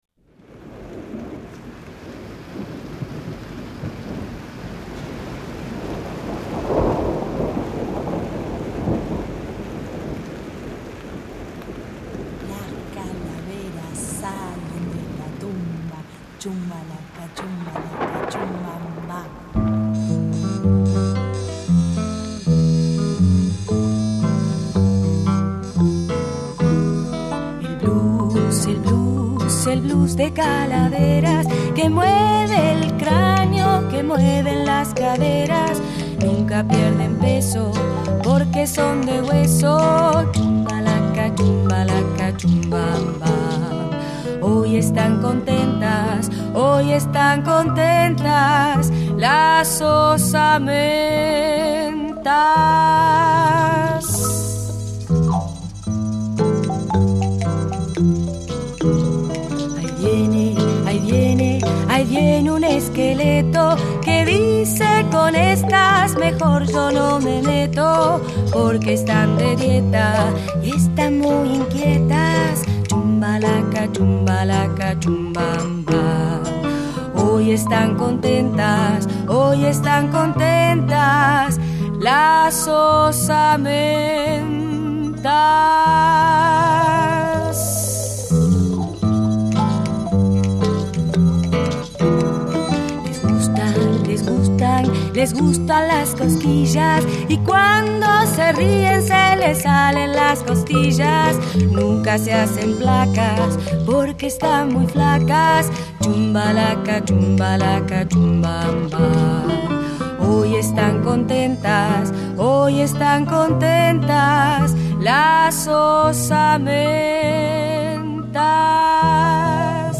flauta dulce